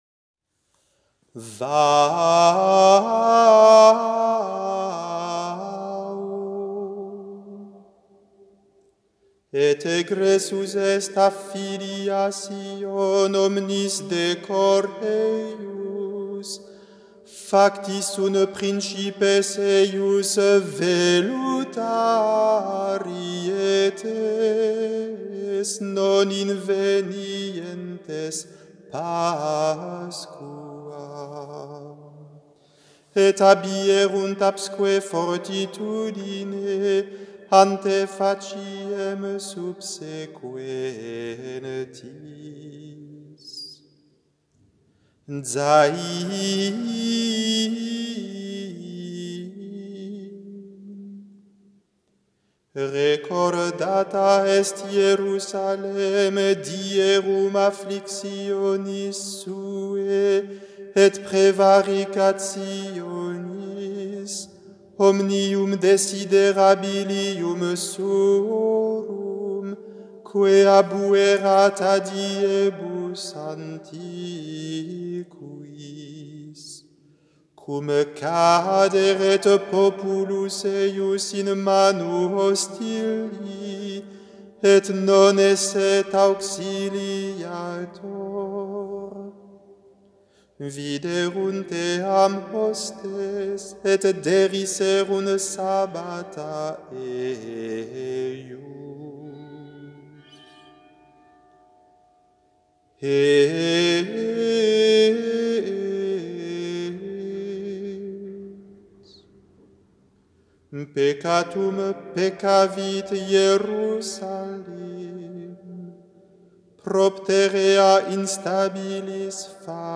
Le chant qui est joint à cet article est une mélodie mozarabe.
Vous reconnaîtrez le début de chaque verset au son de la lettre hébraïque.